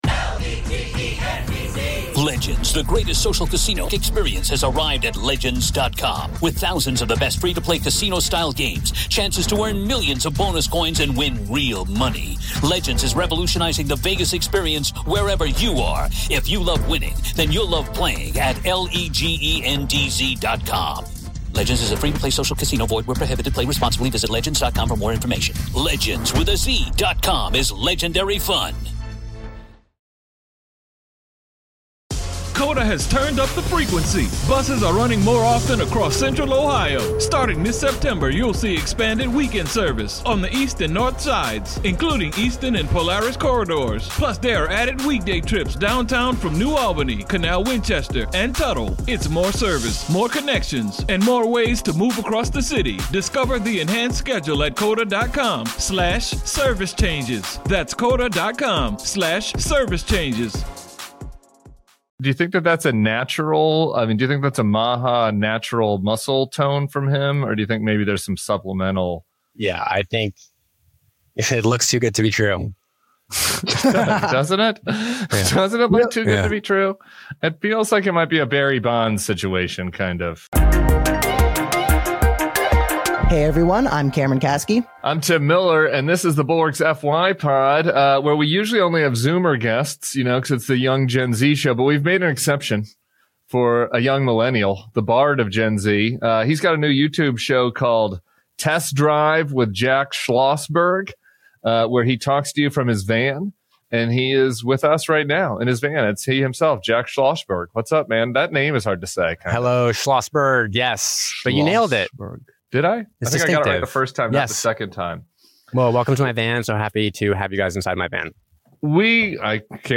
Jack Schlossberg, JFK’s grandson and a rising political voice, joins Cameron Kasky and Tim Miller for a bold, funny, and fiery conversation. He explains why he went viral for calling out his cousin RFK Jr., defends President Biden, and lays out what it means to be a liberal in...